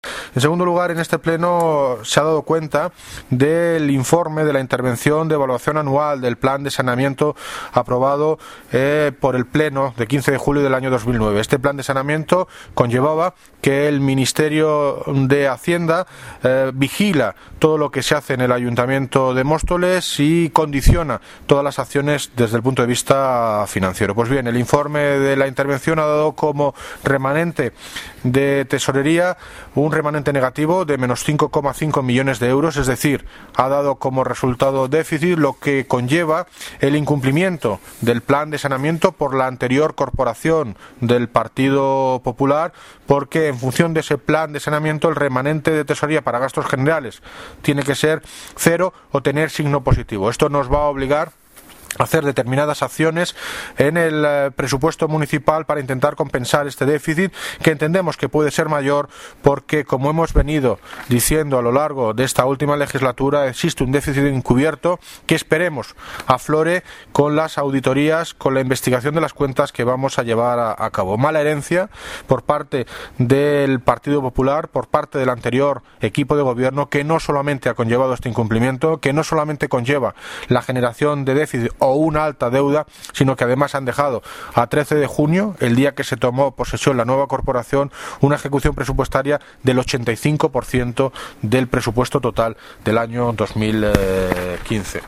Audio de David Lucas, Alcalde de Móstoles
estado de las cuentas ayto mostoles audio David Lucas.mp3